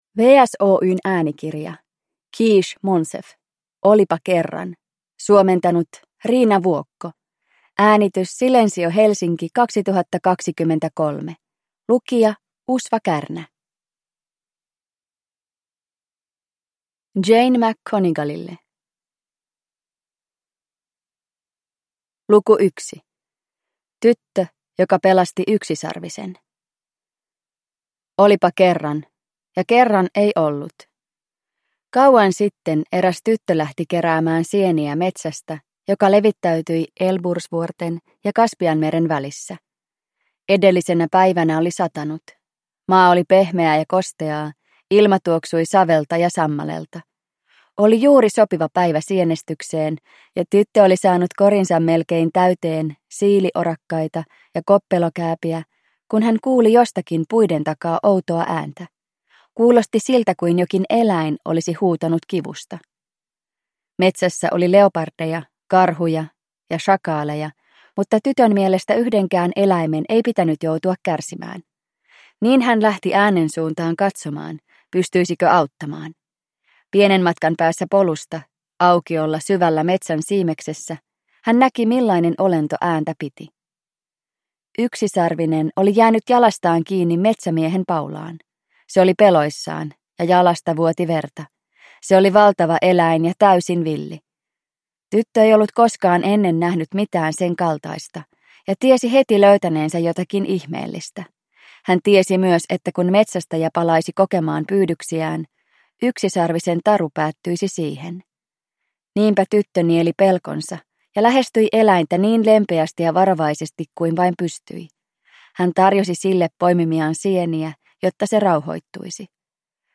Olipa kerran – Ljudbok – Laddas ner